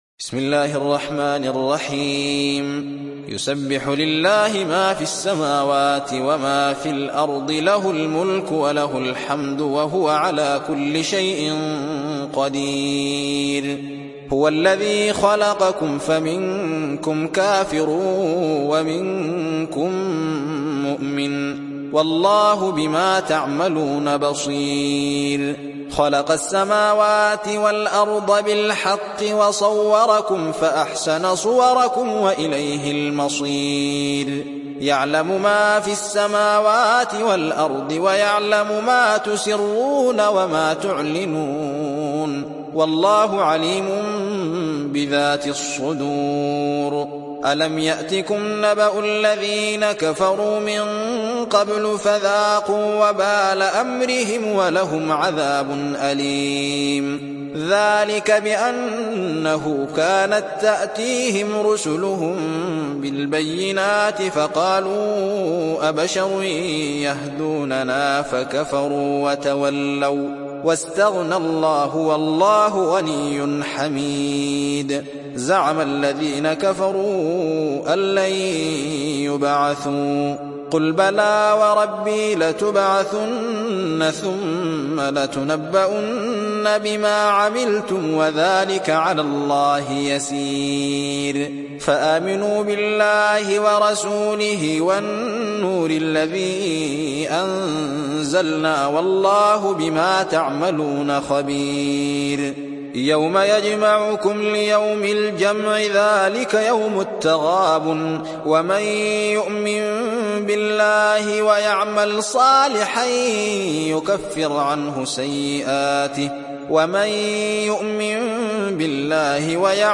Riwayat Hafs dari Asim